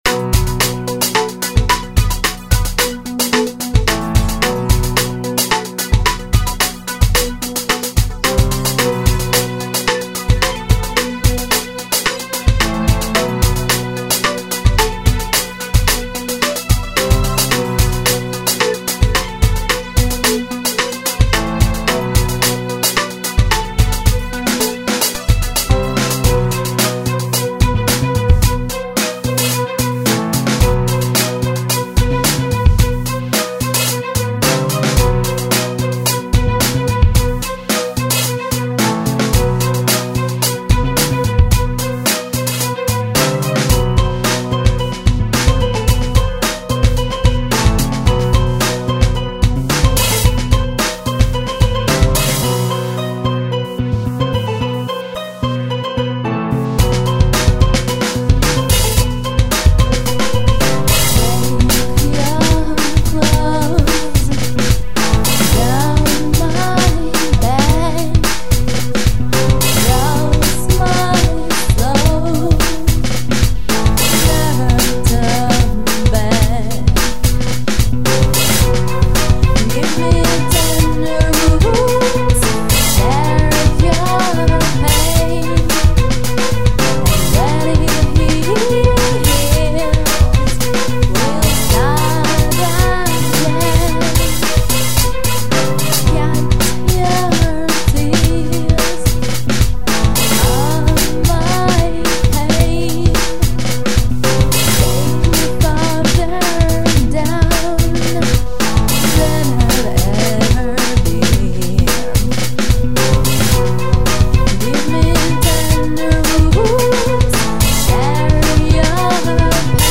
Жанр обощенно зовется стимпанк-мьюзик.